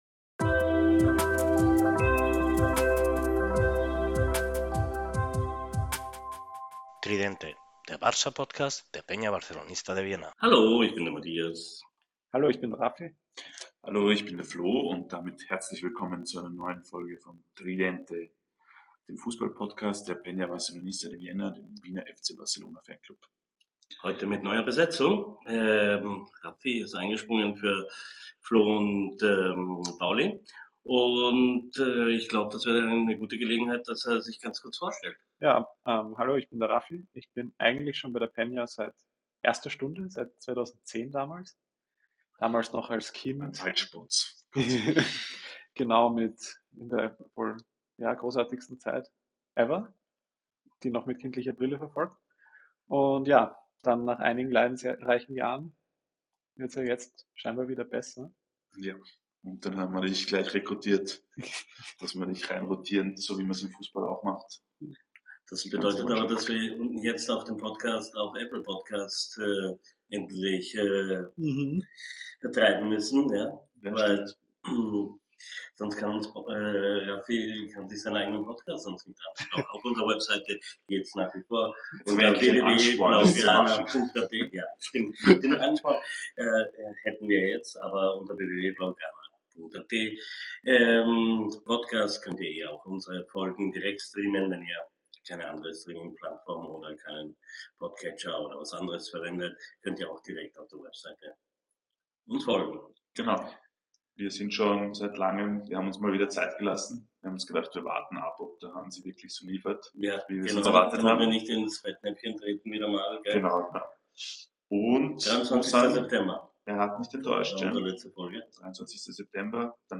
Vorweg, wir arbeiten weiterhin daran die Tonqualität zu verbessern. Nach dem die Königsetappe im Oktober überwunden wurde, tauschen wir Eindrücke über die Entwickluing des Teams und die die bestrittenen Spiele aus.